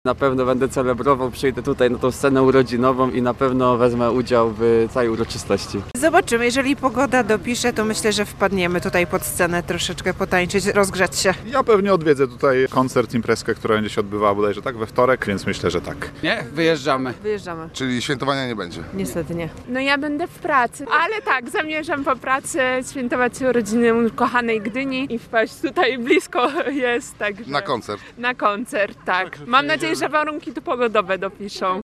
We wtorek Gdynia świętować będzie swoje setne urodziny. Zapytaliśmy mieszkańców, czy planują udział w urodzinowych wydarzeniach: